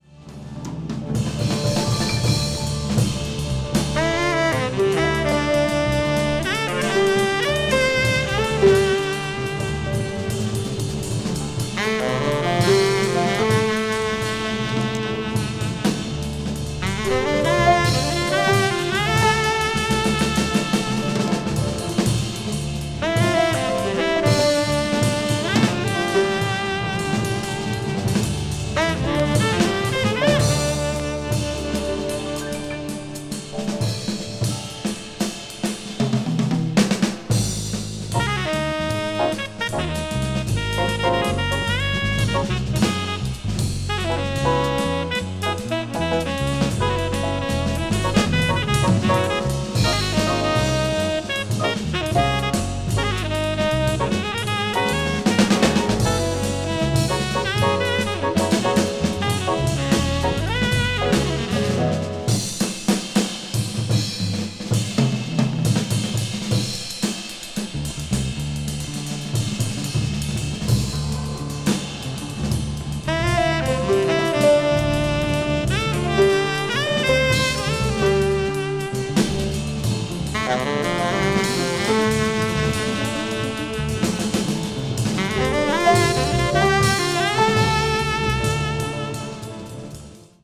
deep jazz   modal jazz   post bop   spiritual jazz